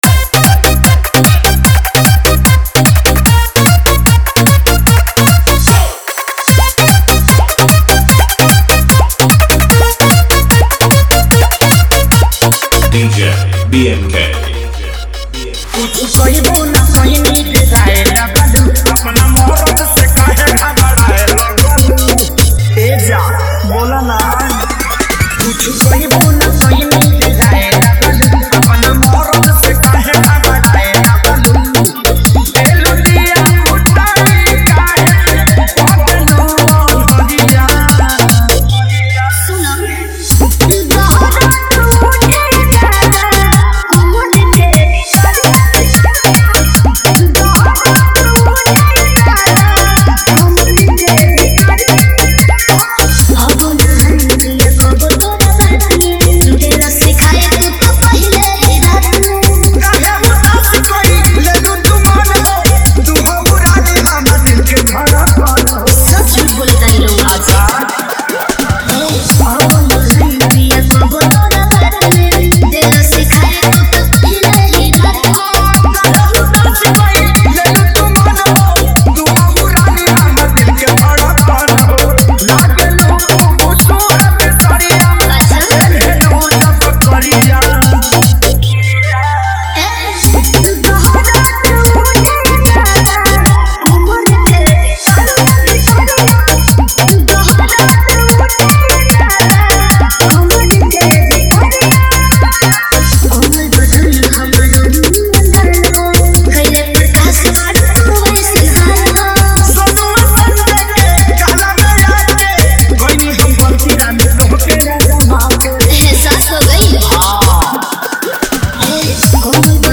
Category : Bhojpuri Dj Remix Jhanjhan Bass